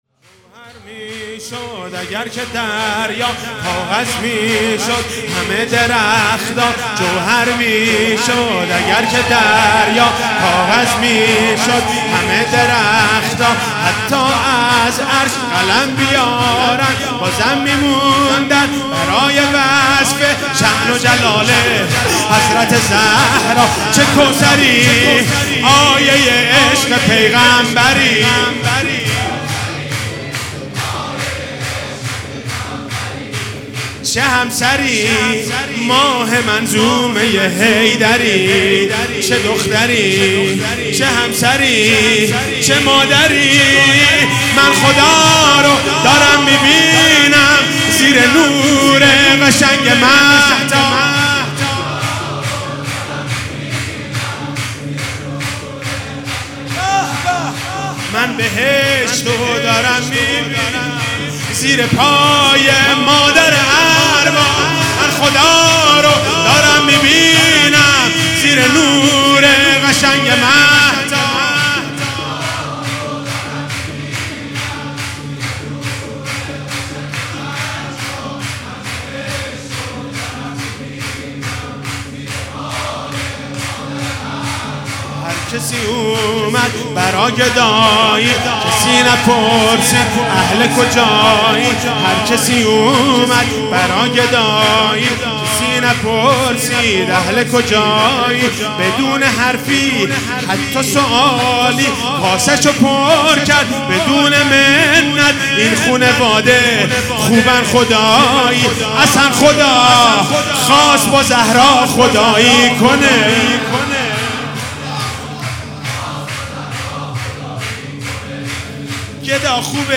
ولادت حضرت زهرا سلام الله علیها1400
جوهر میشد سرود